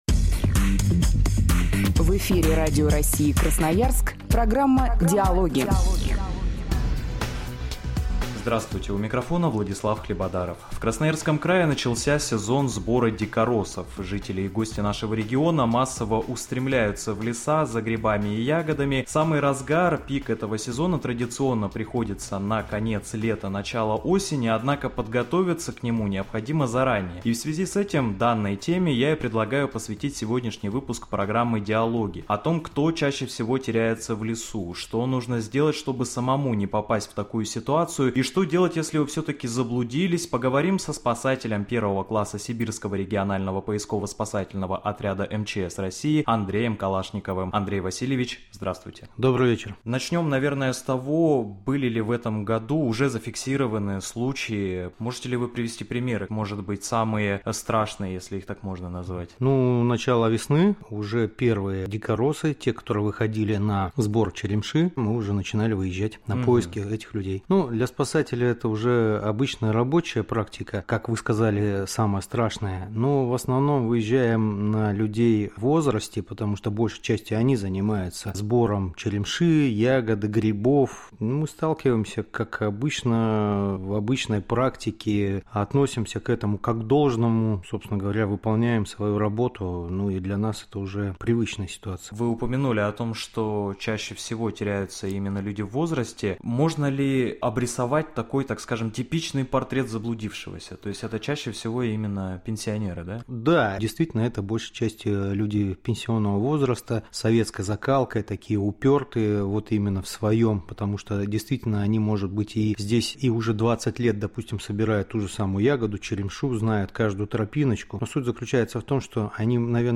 Радио России. Интервью